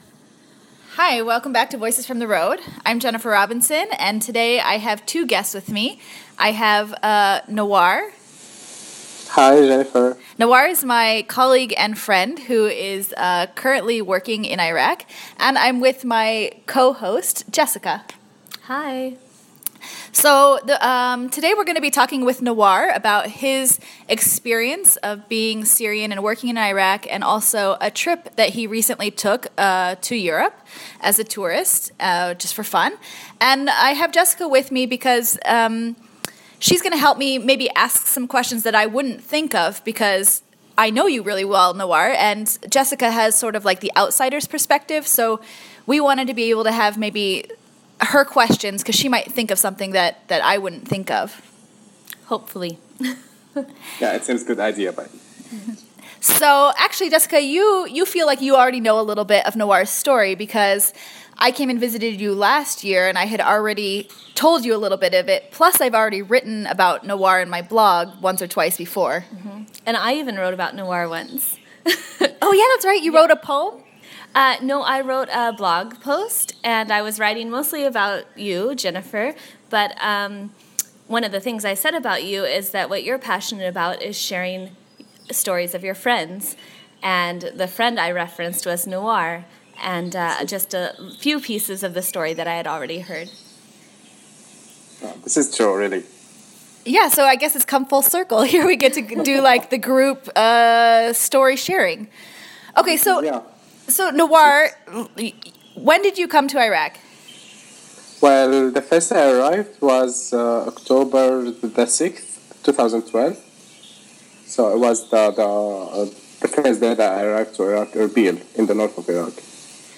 Connecting via Skype, my friend and colleague shares his heart-wrenching story of leaving Syria in order to delay mandatory military service, only to find out days later of the loss of his younger brother, a casualty of the nascent civil war.